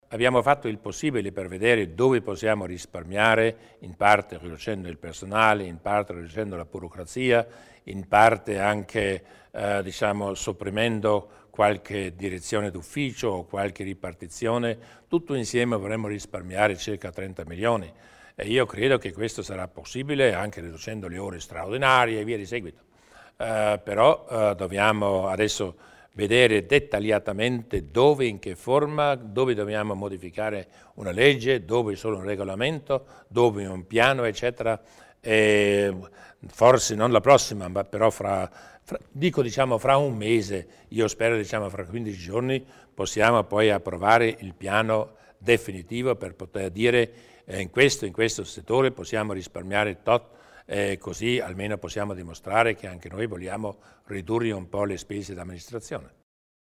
Il Presidente Durnwalder spiega gli interventi a riduzione della spesa